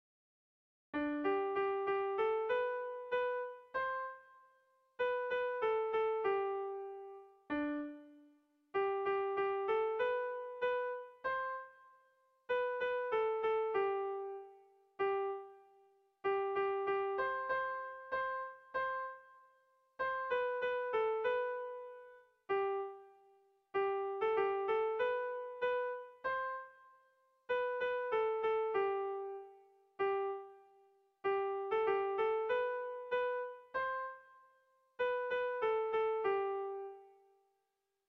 Dantzakoa
AB